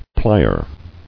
[ply·er]